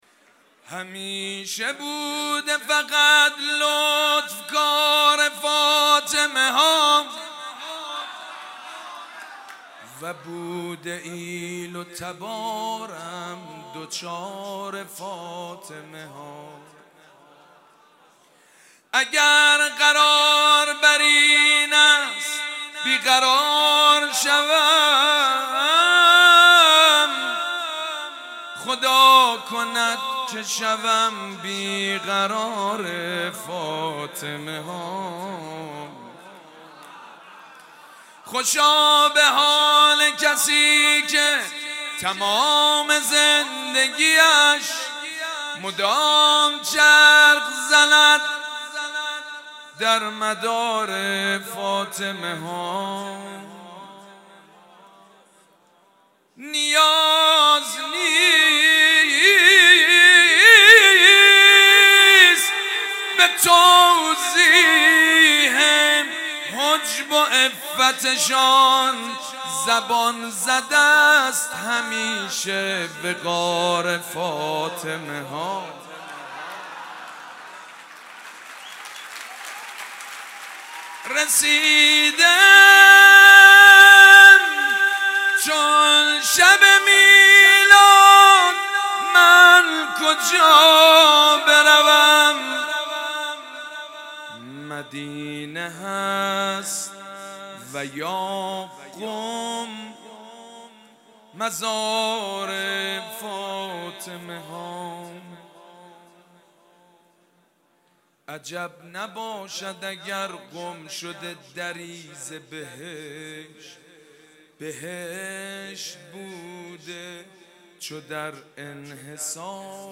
مدح: همیشه بوده فقط لطف کار فاطمه ها